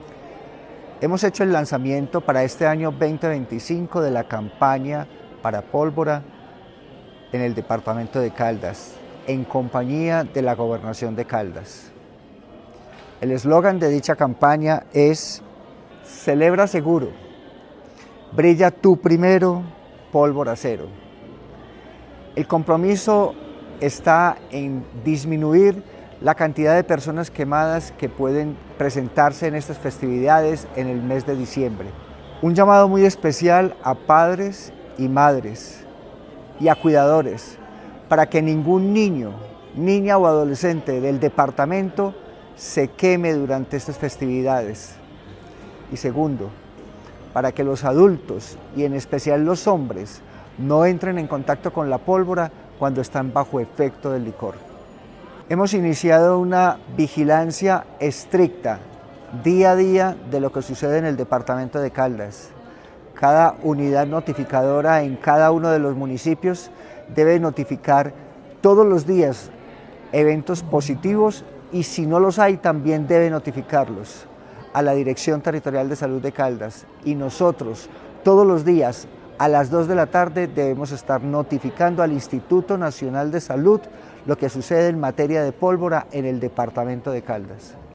La Gobernación de Caldas, en articulación con la Dirección Territorial de Salud de Caldas (DTSC), el Instituto Colombiano de Bienestar Familiar (ICBF) y la Policía Nacional, realizó el lanzamiento oficial de la campaña departamental de prevención del uso de pólvora para la temporada 2025.